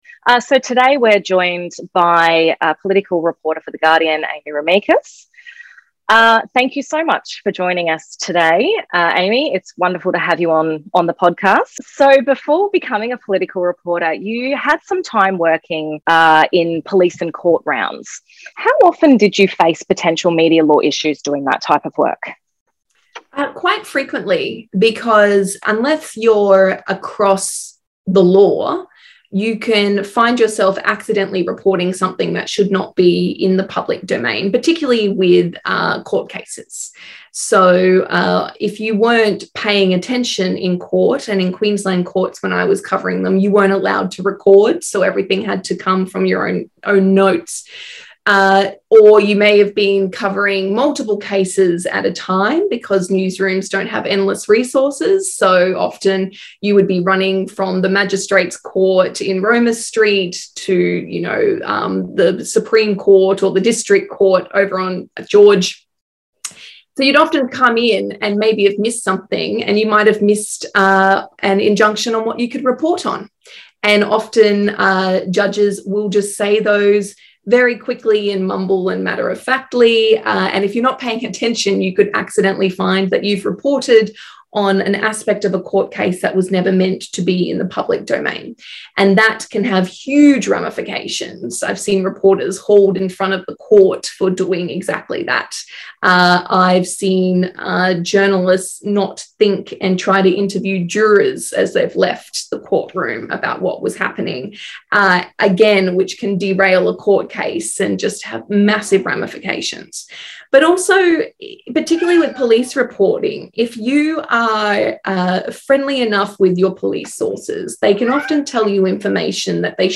Welcome to Griffith SMALL (Social Media And Law Livestream) where we interview experts on a range of aspects of social media law.